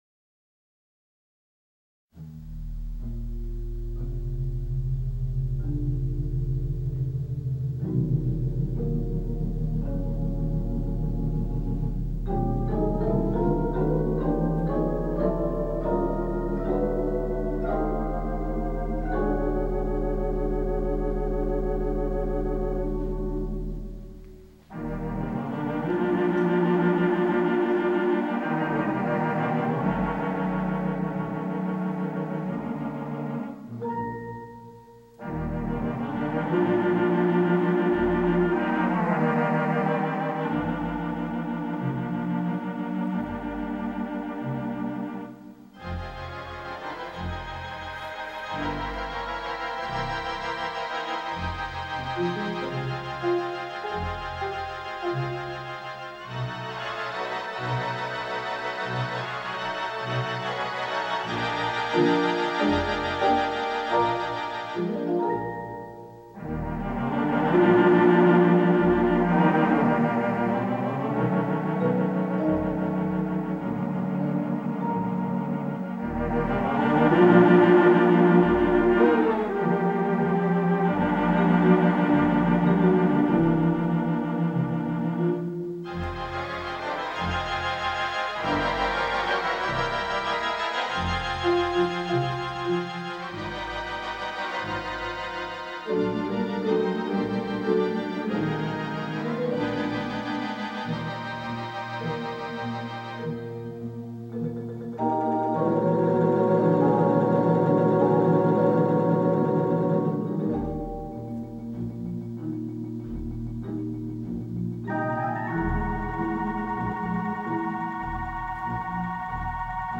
Digital Theatre Organ
The Second Concert